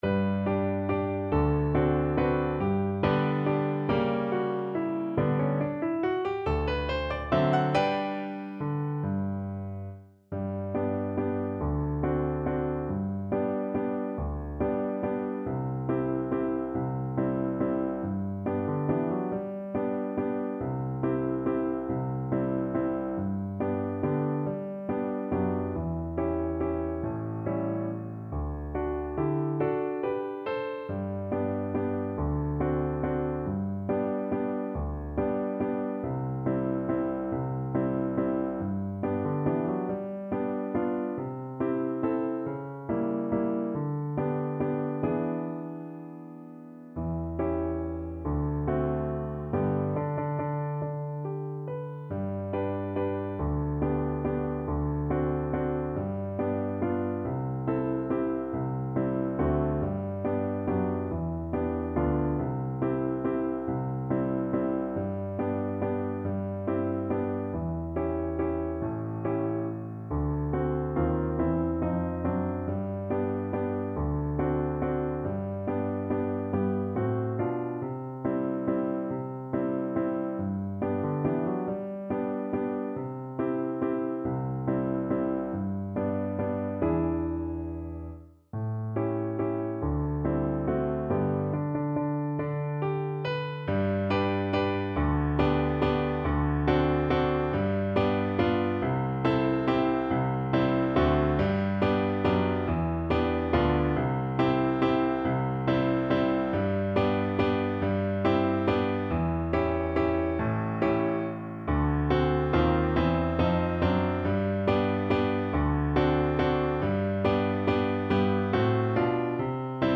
3/4 (View more 3/4 Music)
~ = 140 Tempo di Valse
Pop (View more Pop Flute Music)